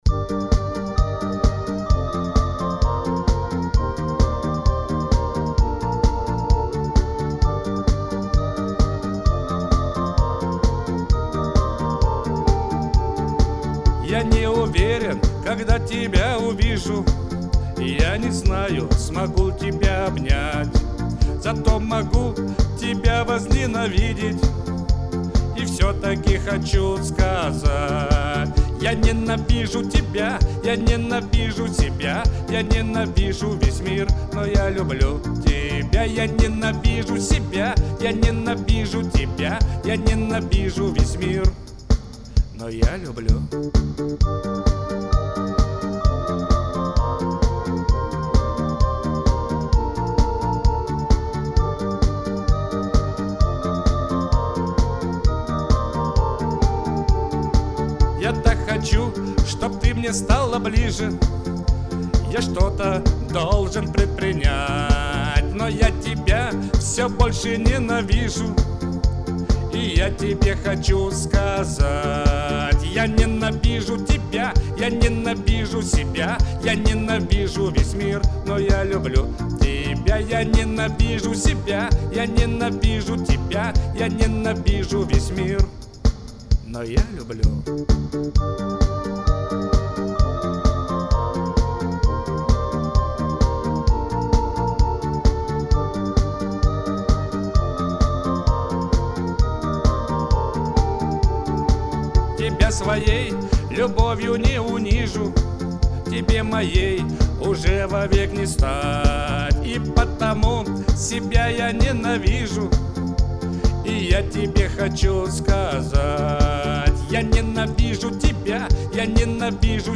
Какой он голосистый!